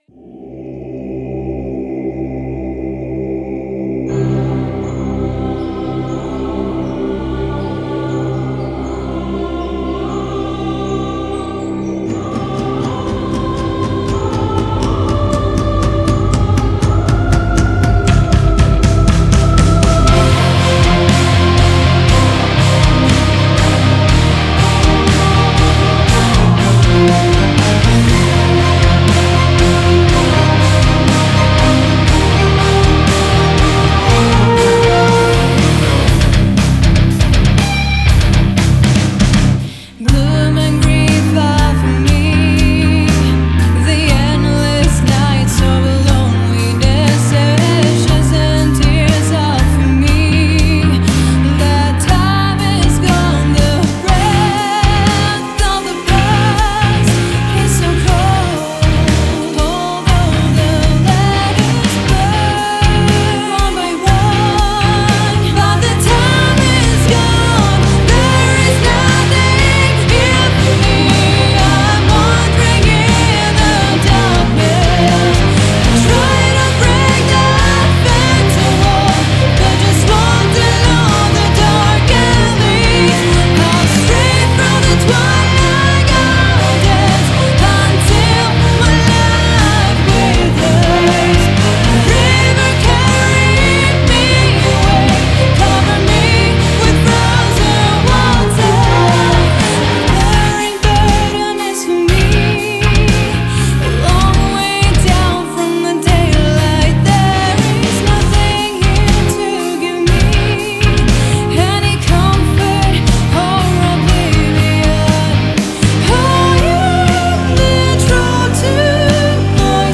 Мелодик метал, что бы доработать?